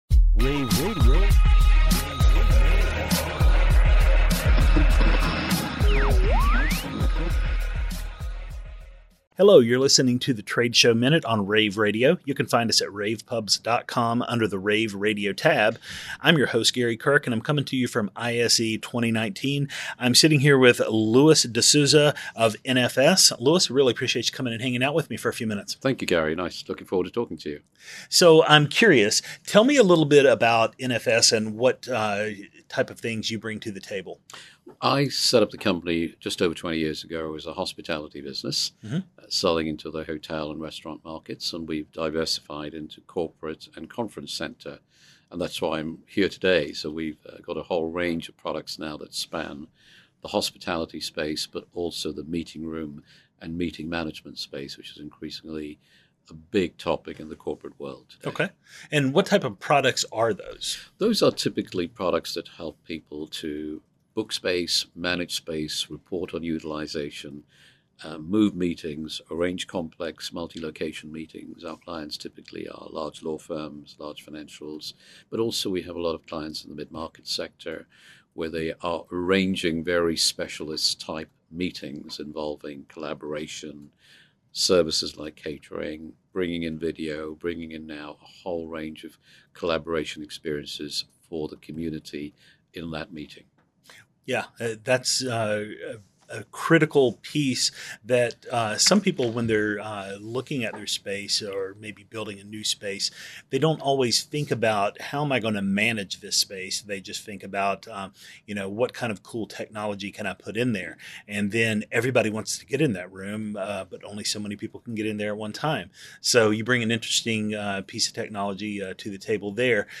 interviews
February 6, 2019 - ISE, ISE Radio, Radio, rAVe [PUBS], The Trade Show Minute,